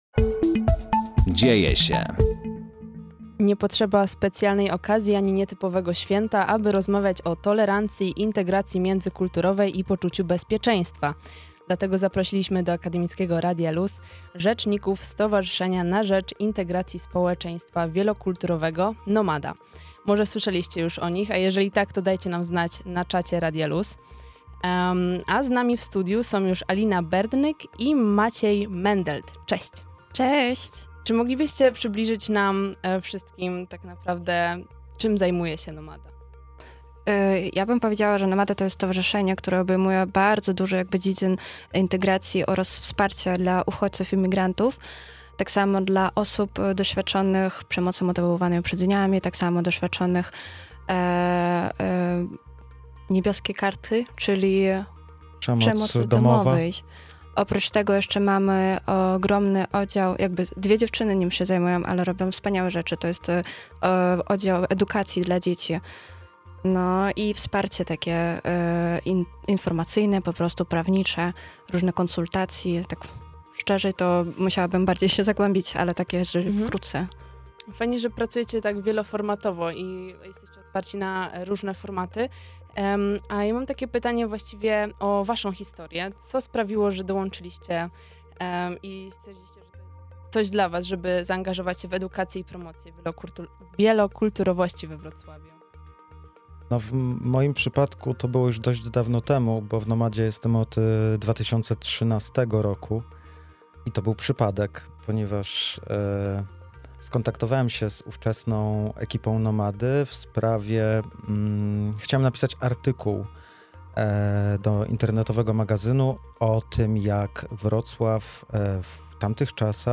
O tym, że wcale tak być nie musi, przypominają nam goście ze Stowarzyszenia działającego na rzecz integracji społeczeństwa wielokulturowego - Nomada. O ich początkach w organizacji, ewoluujących zakresach obowiązków oraz nadchodzących inicjatywach rozmawiamy na antenie Akademickiego Radia LUZ.